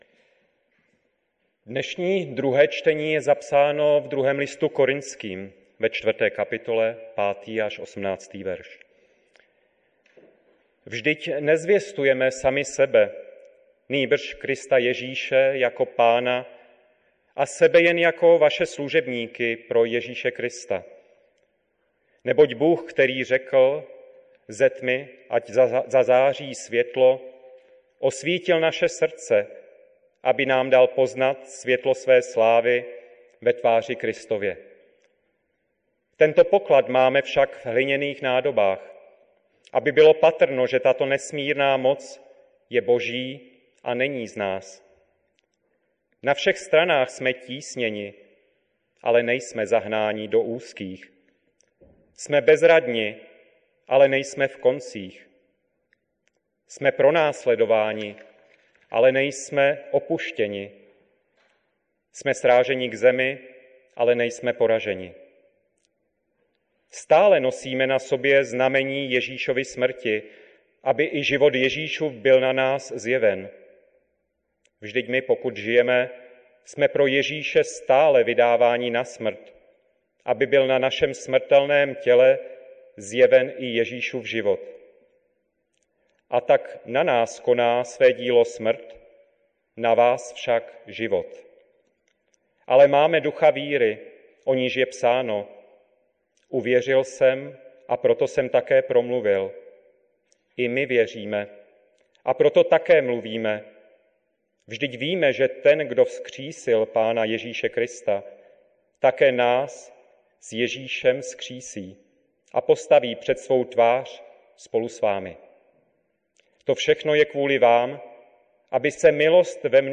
Ekumenická bohoslužba 2024